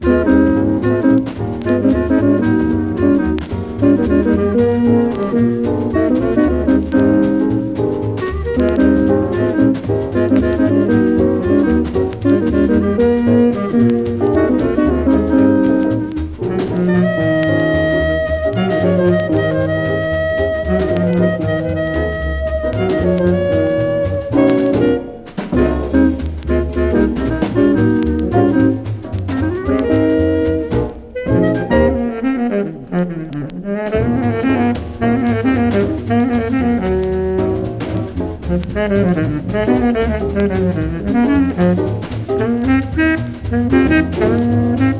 粒立ちの良い音が軽やかかつ流麗に、ときにスウィンギンに。
Jazz　USA　12inchレコード　33rpm　Mono